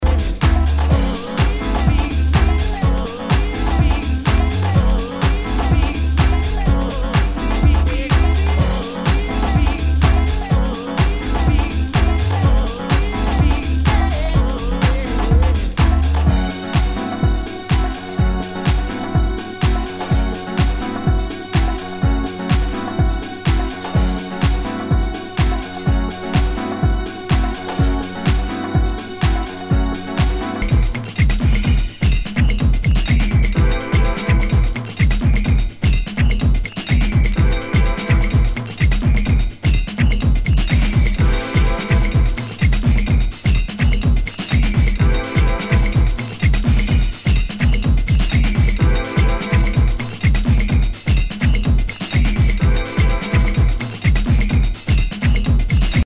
HOUSE/TECHNO/ELECTRO
ナイス！ディープ・ハウス・クラシック！
[VG ] 平均的中古盤。スレ、キズ少々あり（ストレスに感じない程度のノイズが入ることも有り）